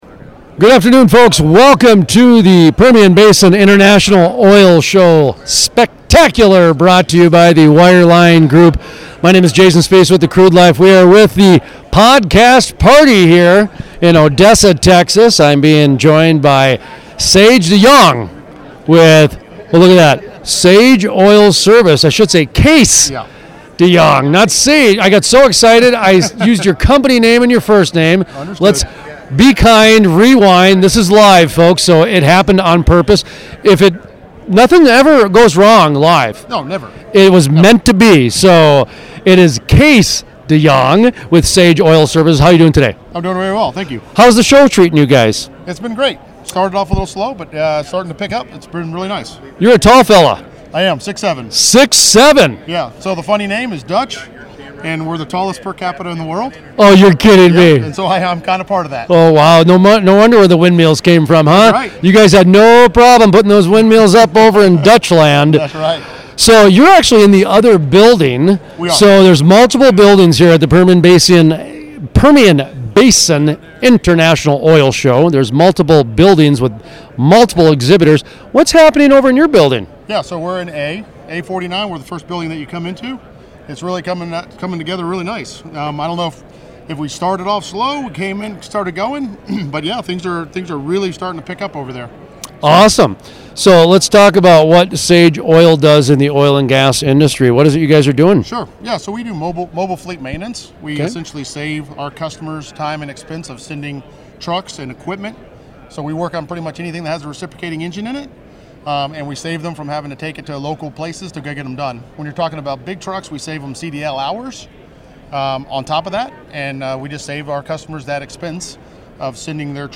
Full Length Interviews
Featured Music:  Moody River Band